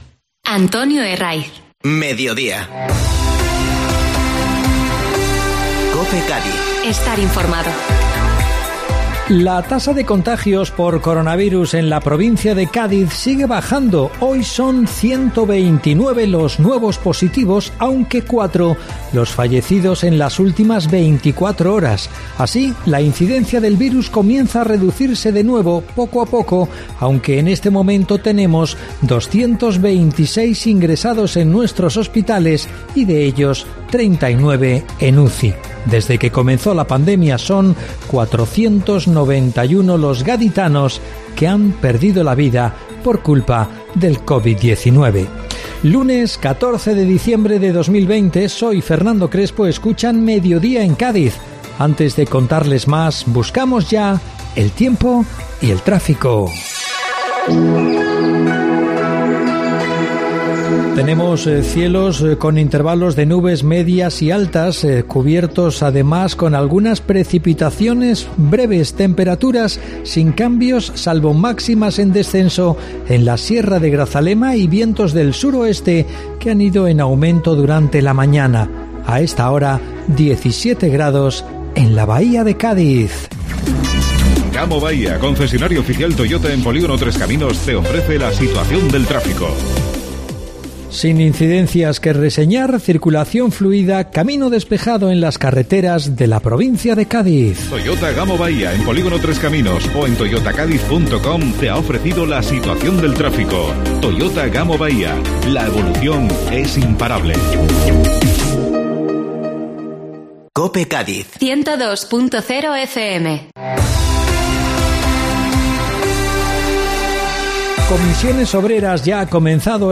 Informativo Mediodía COPE Cádiz (14-12-20)